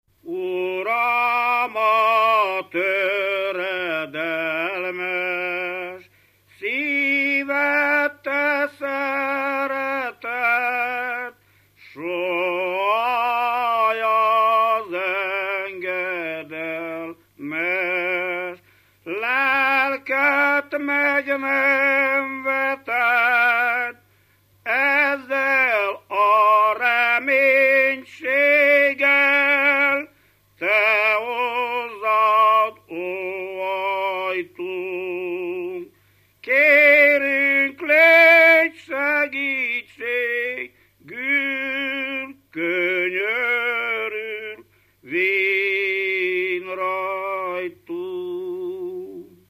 Dunántúl - Szerém vm. - Kórógy
ének
Stílus: 7. Régies kisambitusú dallamok
Kadencia: 4 (4) 1 1